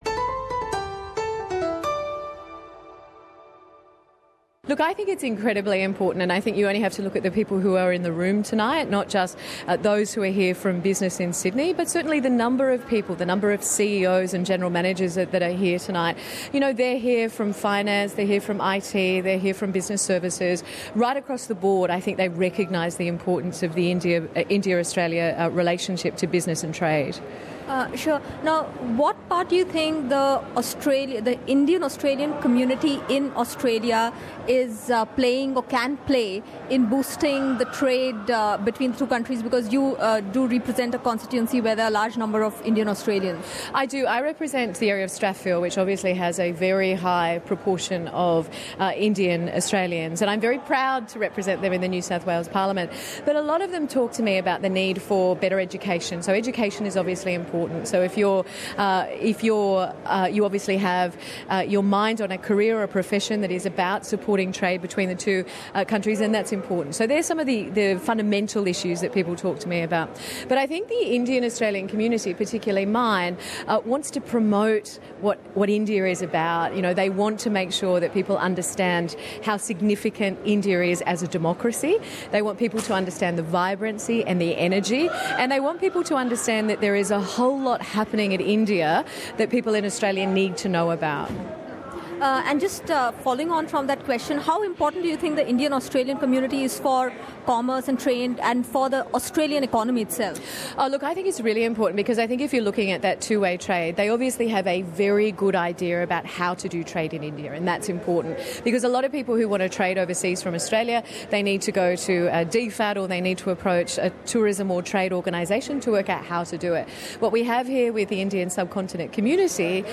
NSW MP from Strathfield Jodi McKay recently shared with us, at the AIBC Annual Dinner, among other things her love for Sarees. Tune in for this free flowing chat with Jodi McKay to know what she thinks about the Australia-India relationship.